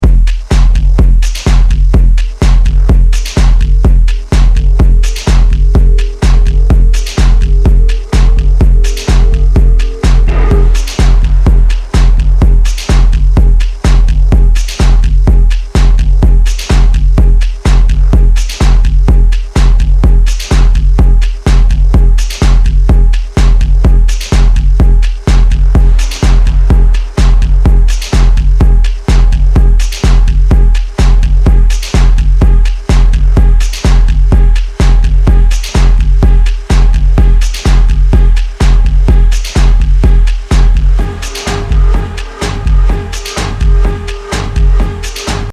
pre-Goth punk monster